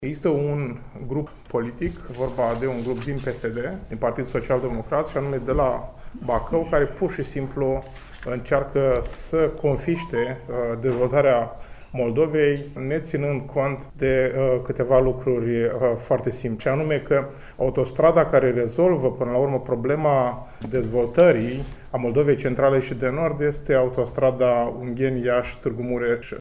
Într-o conferinţă de presă, susţinută la Iaşi de asociaţiile „Împreună pentru A8” şi „Moldova vrea autostradă”, reprezentanţii acestor organizaţii civice au demontat informaţiile, vehiculate de Ministerul Transporturilor şi necontrazise de Compania Naţională de Administrare a Infrastructurii Rutiere, privind faptul că A8, Ungheni – Iaşi – Tîrgu Mureş ar trebui înlocuită cu A13, care ar urma să lege Bacăul de Braşov.